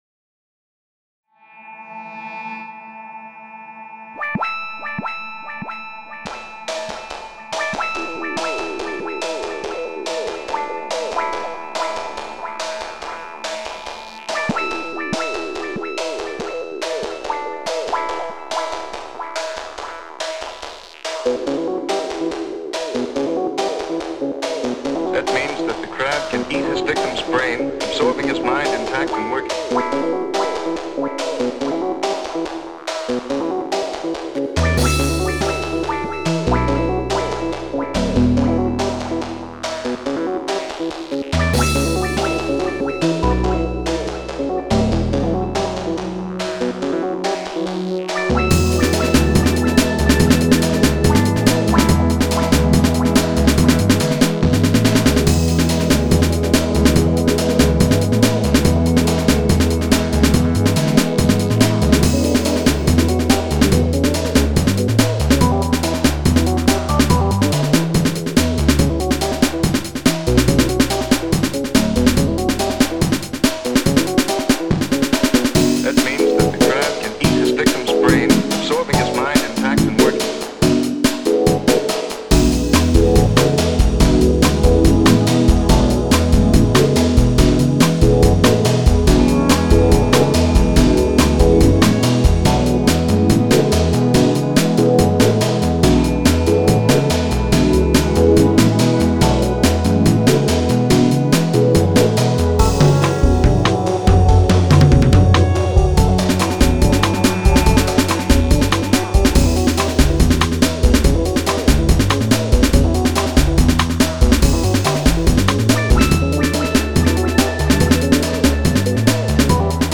Genre Lounge